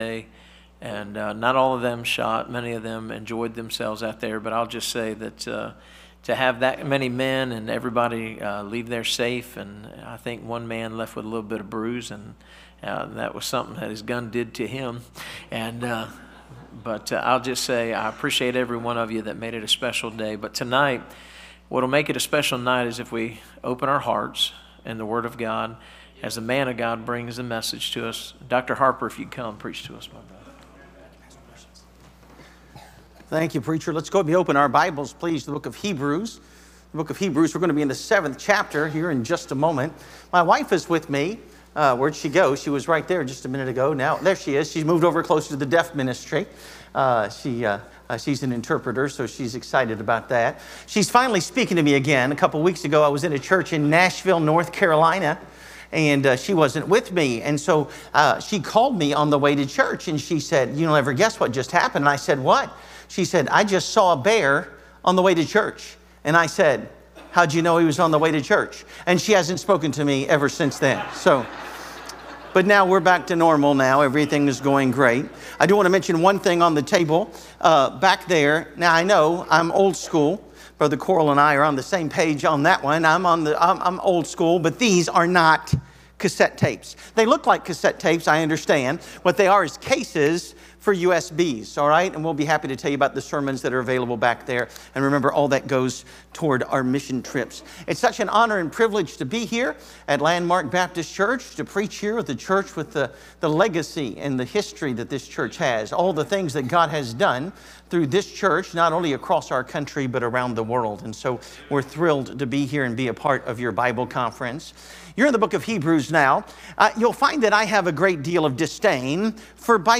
Series: 2025 Bible Conference